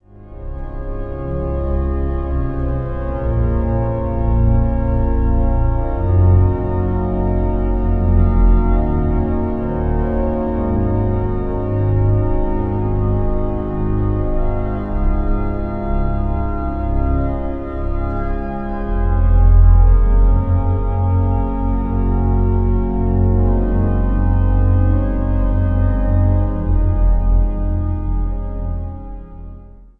Improvisatie op zendingspsalmen.